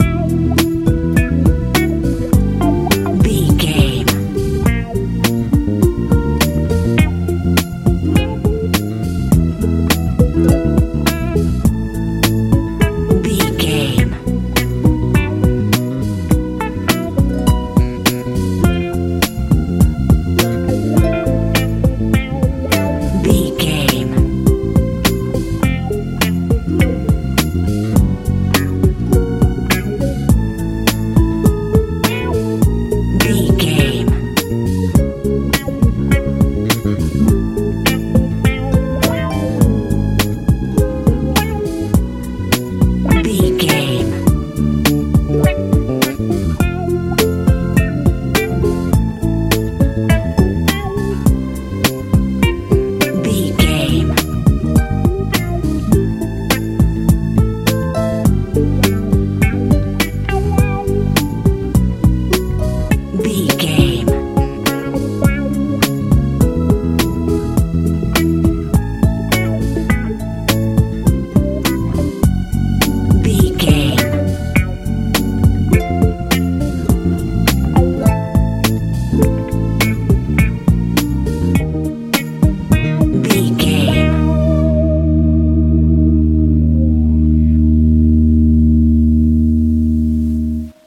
modern pop feel
Ionian/Major
groovy
funky
synthesiser
electric guitar
harp
bass guitar
drums
70s
80s